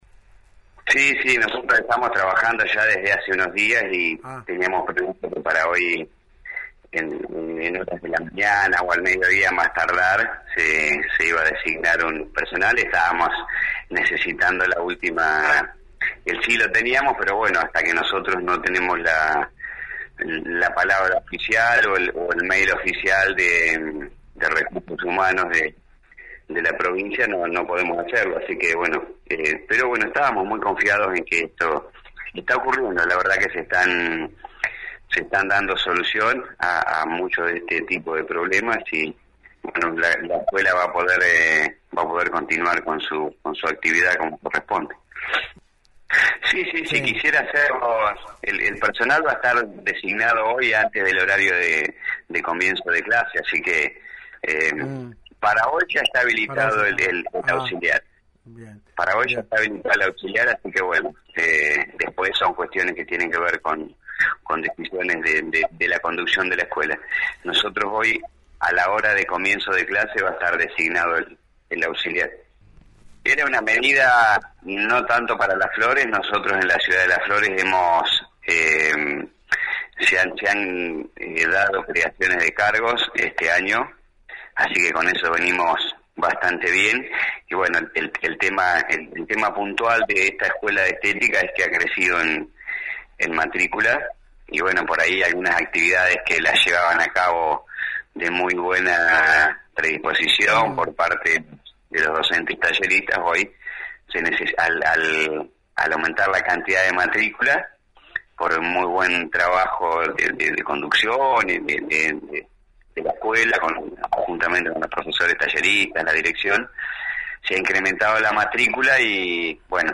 Prof. Eduardo Sández (Presidente Consejo Escolar Las Flores):